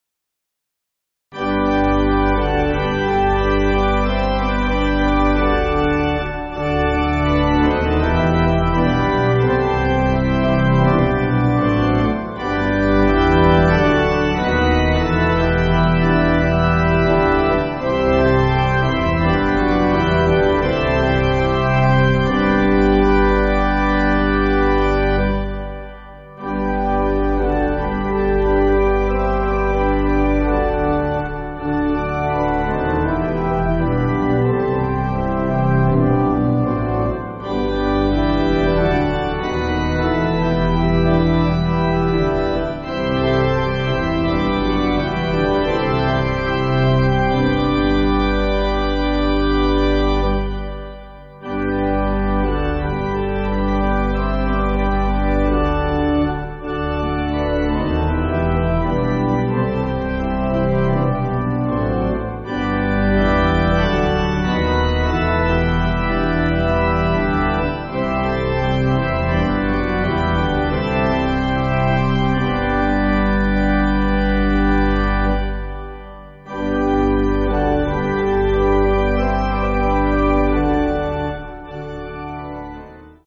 (CM)   5/G